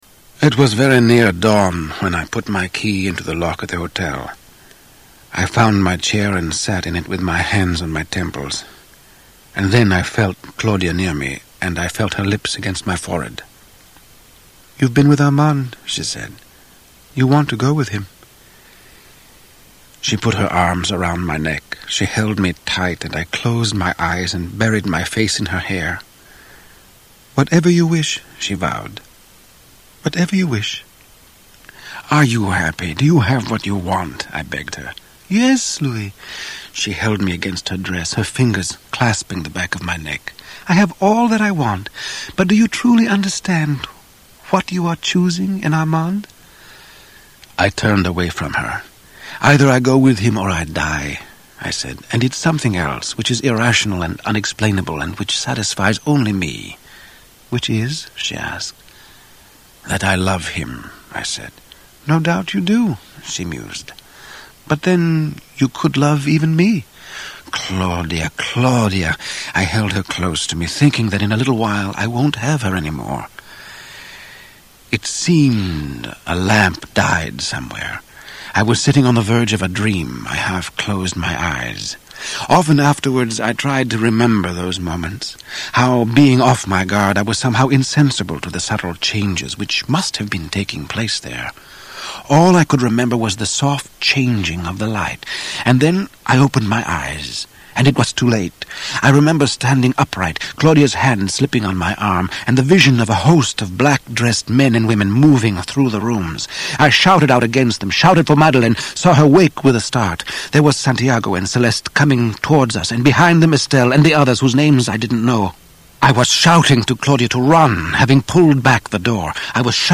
Tags: Media Author Anne Rice Interview with the Vampire Audio Books